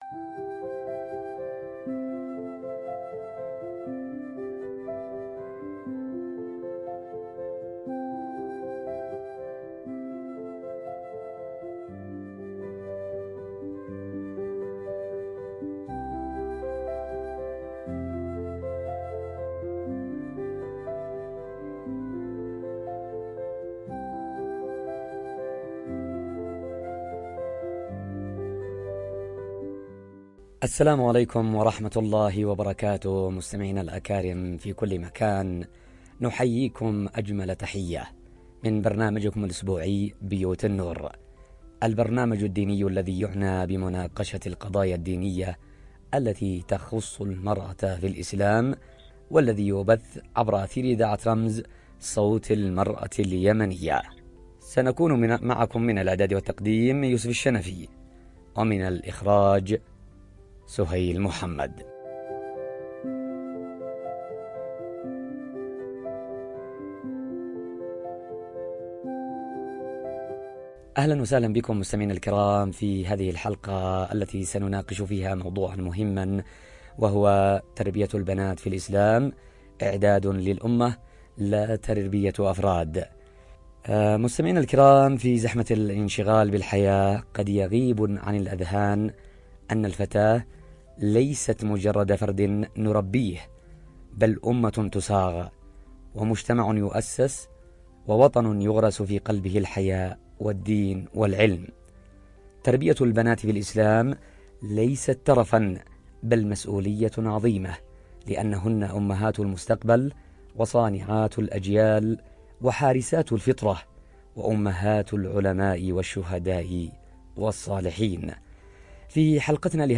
نقاش تربوي وفكري
عبر أثير إذاعة رمز